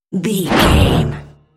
Sci fi electronic whoosh
Sound Effects
Atonal
futuristic
high tech
whoosh